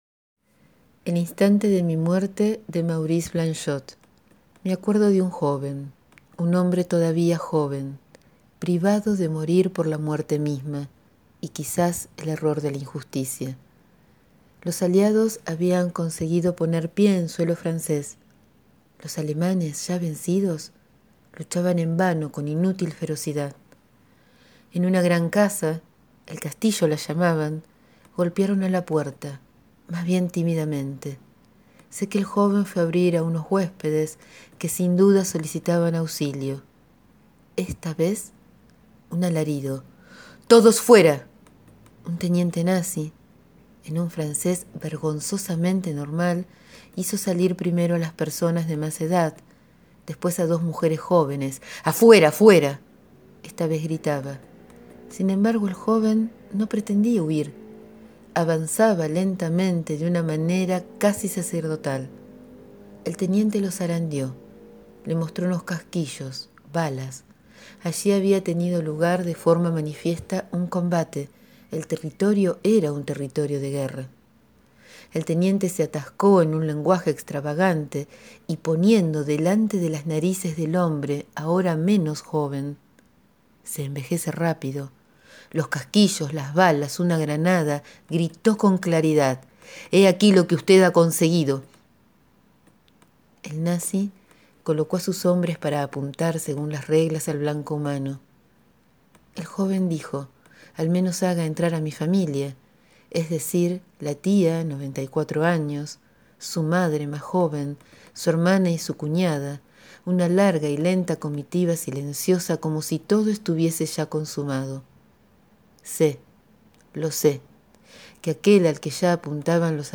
Dejo mi voz a este relato que no fue fácil leer pero también fue transformador.